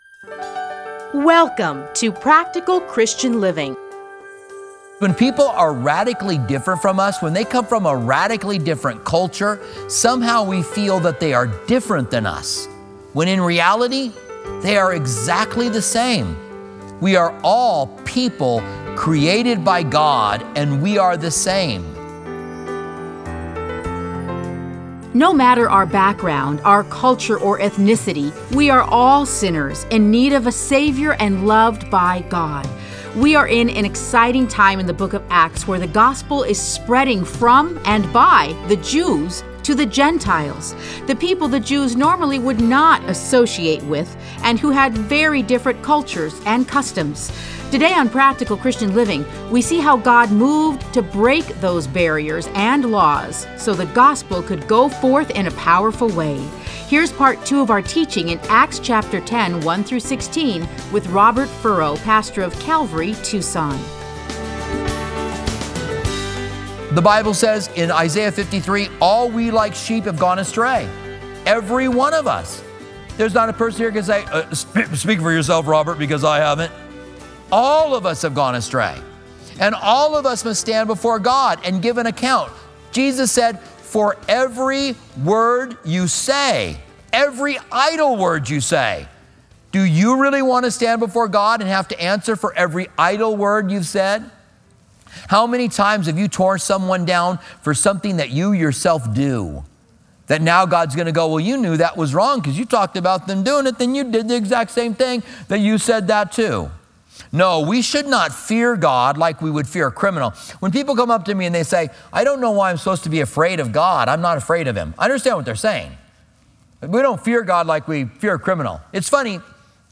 Listen to a teaching from Acts 10:1-16.